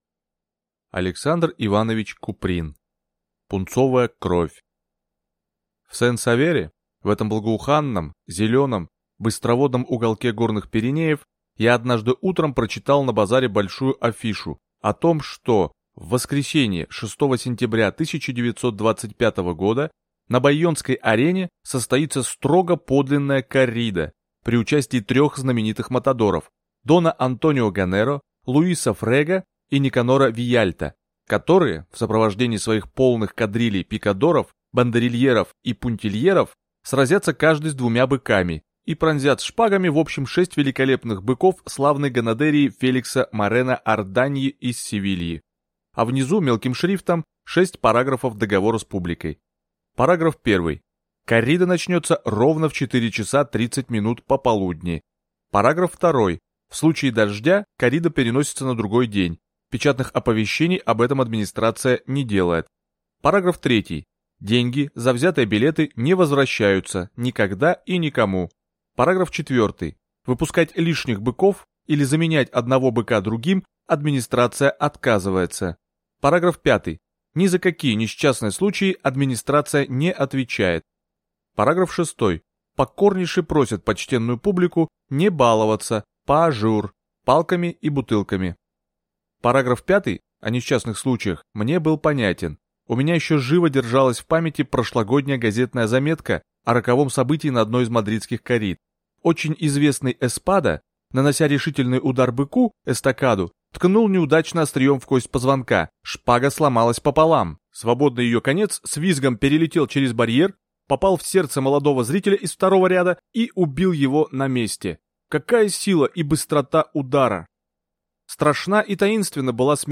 Аудиокнига Пунцовая кровь | Библиотека аудиокниг